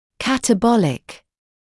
[ˌkætə’bɔlɪk][ˌкэтэ’болик]катаболический